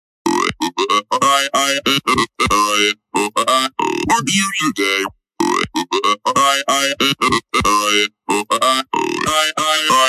Firtana ou oi oy oe ey ey meme soundboard clip with chaotic, high-energy vocal sounds, perfect for funny and absurd reaction